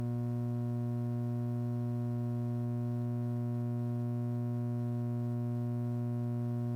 Below is the Null Point the same as the graph above but the Ground Loop Hum Filter is bypassed.
This is a noisy Null Point, the 60/120 hz loop-hum is obviously heard and seen using this program
hum.mp3  84k  It's subtle but annoying, listen.
hum-noise.mp3